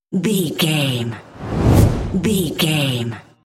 Whoosh fire ball
Sound Effects
dark
intense
whoosh